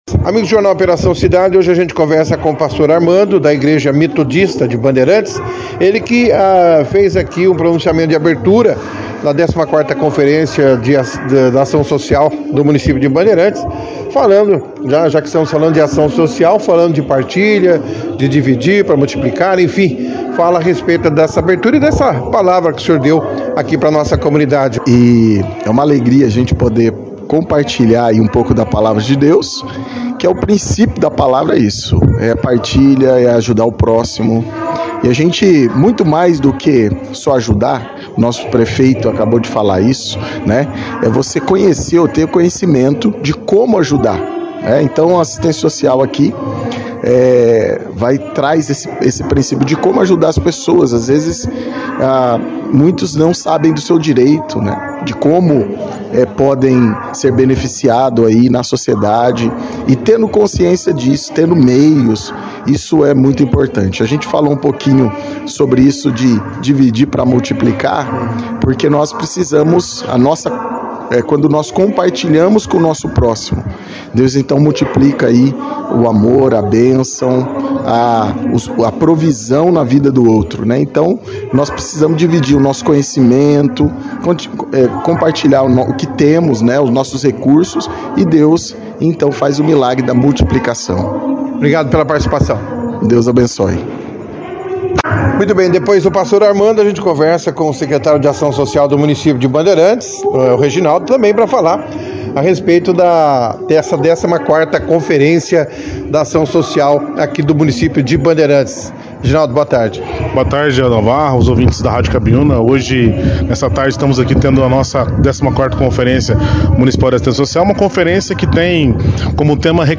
A conferência, foi destaque na edição desta sexta-feira, 23/06, do jornal Operação Cidade